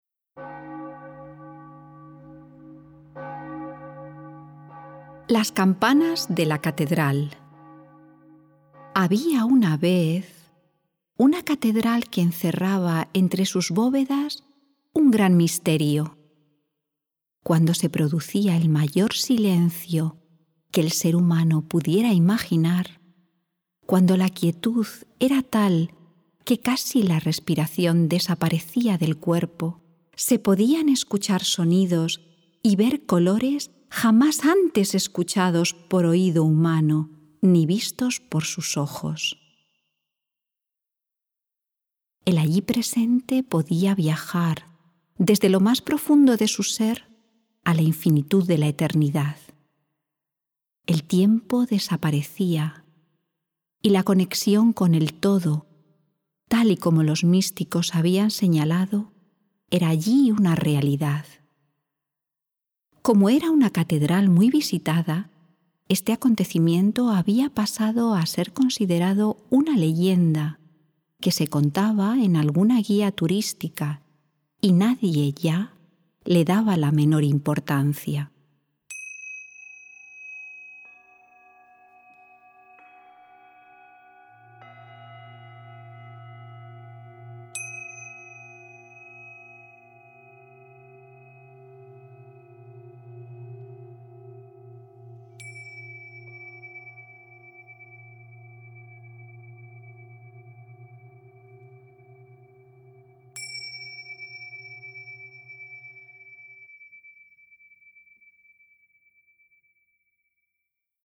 El audio-libro está compuesto por 31 páginas en formato Pdf y 18 pistas de locución y música.